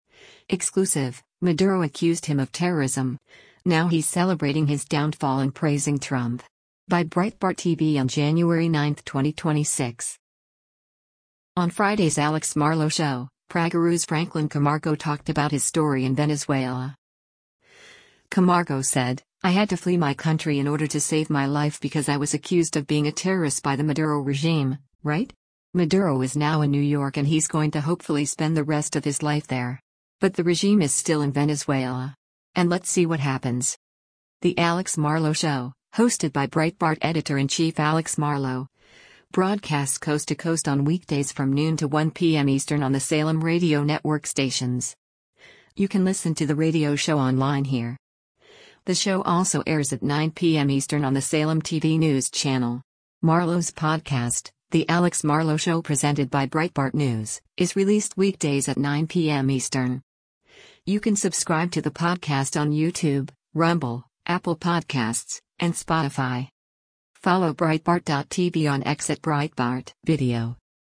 The Alex Marlow Show, hosted by Breitbart Editor-in-Chief Alex Marlow, broadcasts coast to coast on weekdays from noon to 1 p.m. Eastern on the Salem Radio Network stations.